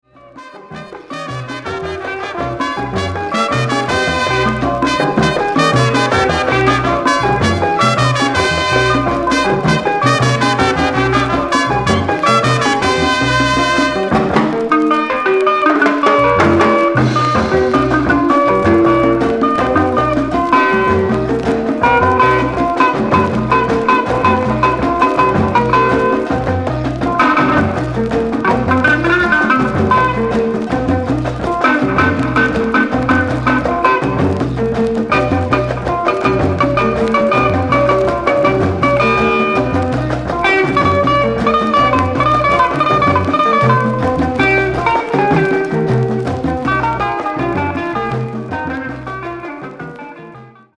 キーワード：アフリカ　サイケ 　ファンク